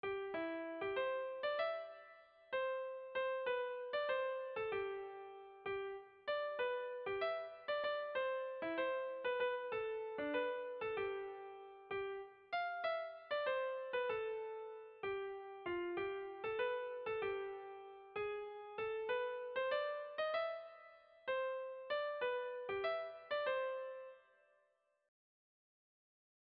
Irrizkoa
Zortziko txikia (hg) / Lau puntuko txikia (ip)
ABDE